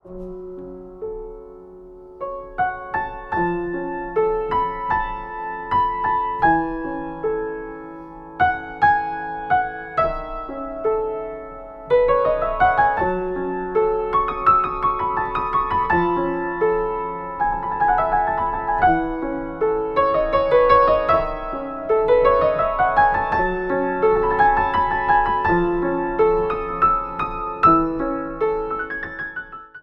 Neue Musik
Tradition/Moderne
Sololiteratur
Klavier (1)